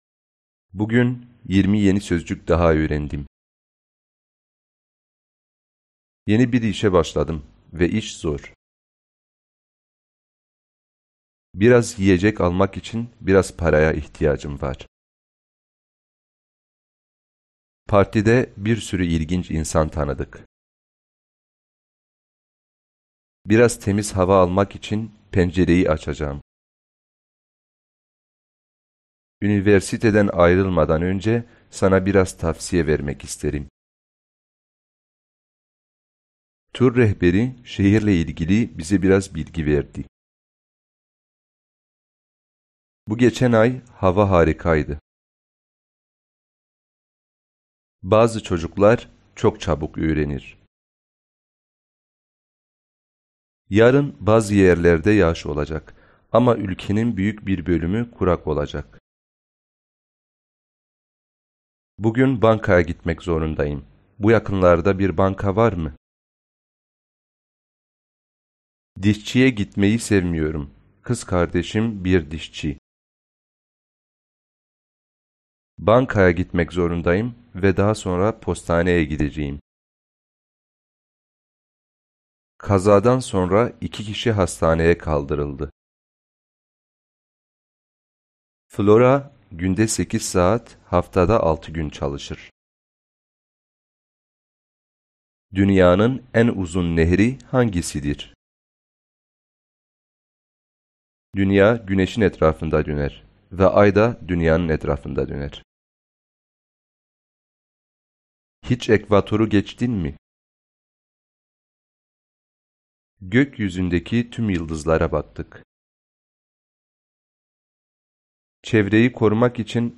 Меня всё еще интересует, чтобы вы на слух, даже там, где какие-то не очень знакомые слова есть, могли понимать дяденьку нашего.